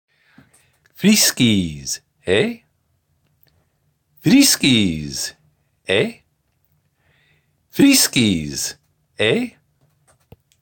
French-Canadian